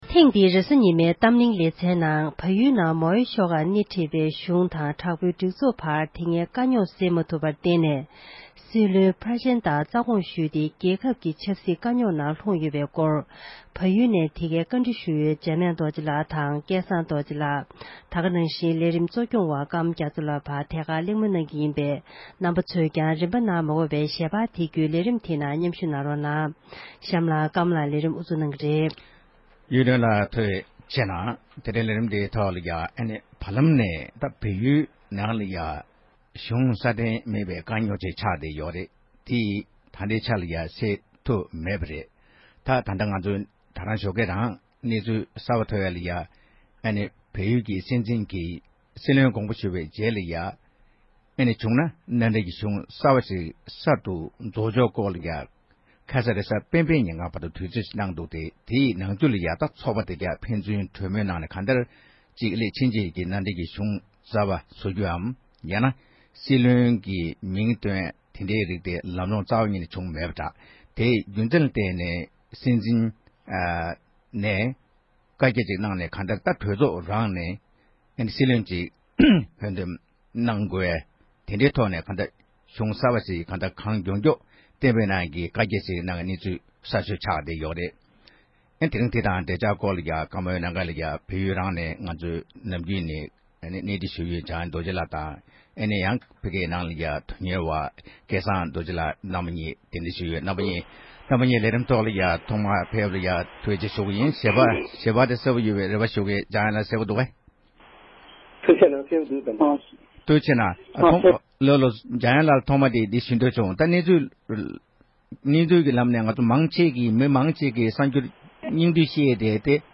བལ་ཡུལ་གྱི་སྲིད་བློན་ལས་གནས་ནས་དགོངས་ཞུང་གནང་ནས་དཀའ་རྙོག་ནང་ལྷུངས་ཡོད་པའི་སྐོར་ལ་གླེང་མོལ་ཞུས་པ།